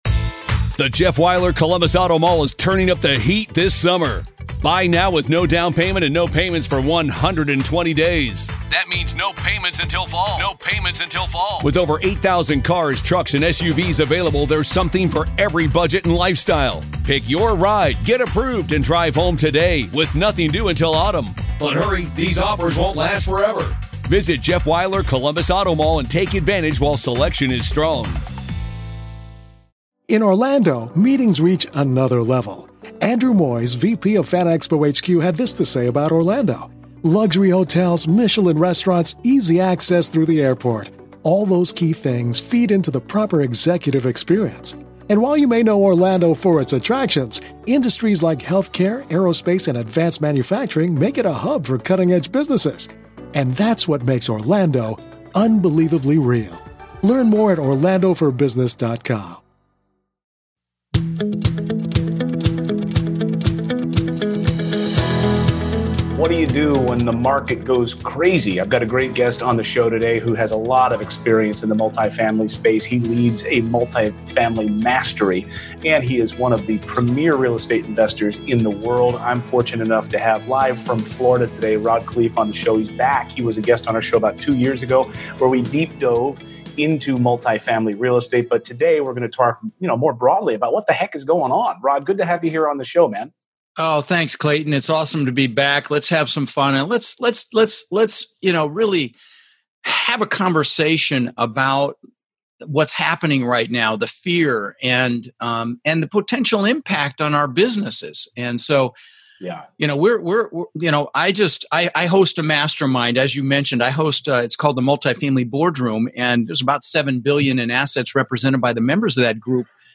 In this interview, you're going to learn how to keep your head about you while still building your portfolio, even if it feels like a world on fire.